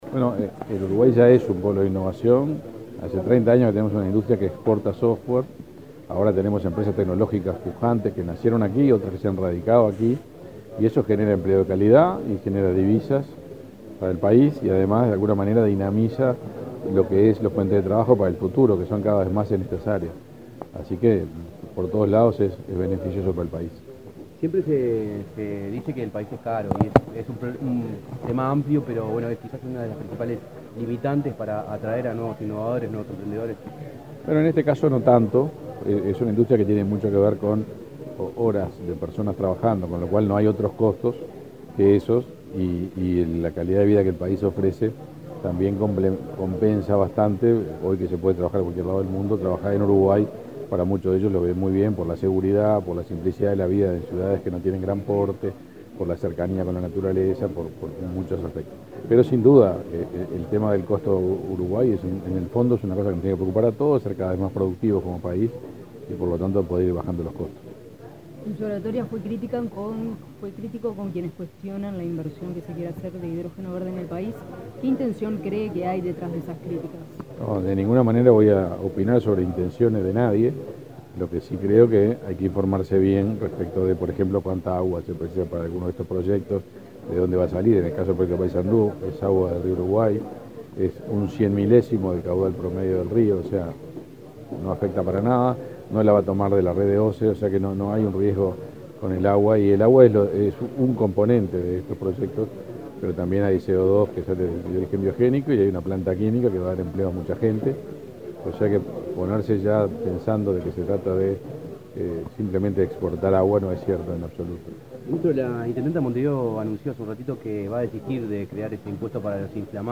Declaraciones del ministro de Industria, Omar Paganini
El ministro de Industria, Omar Paganini, dialogó con la prensa, luego de disertar en un almuerzo organizado por la Cámara de Comercio Uruguay-Estados